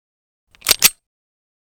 cz52_unjam.ogg